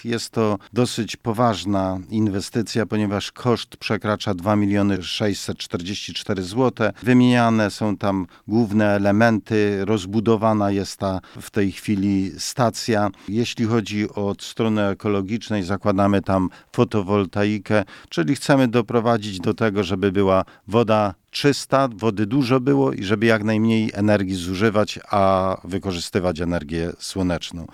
W miejscowości Rzędzianowice trwa właśnie modernizacja ujęć i stacji uzdatniania wody. Mówi wójt gminy Mielec, Józef Piątek.